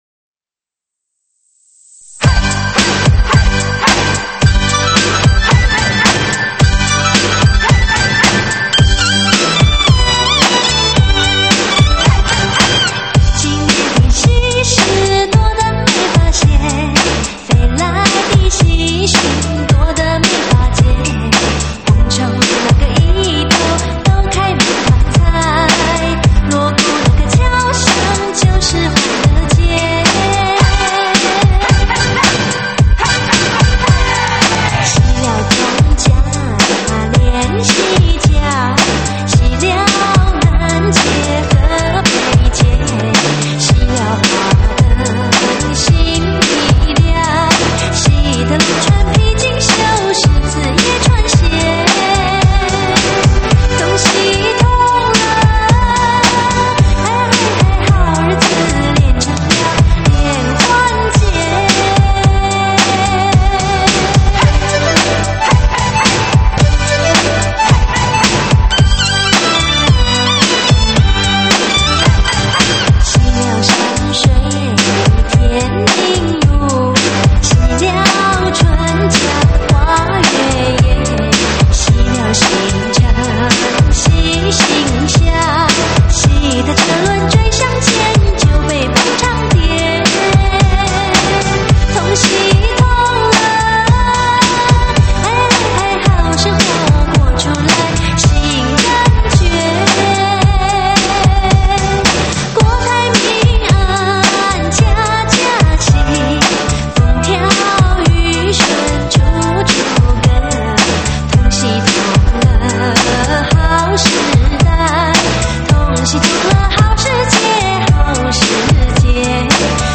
新年喜庆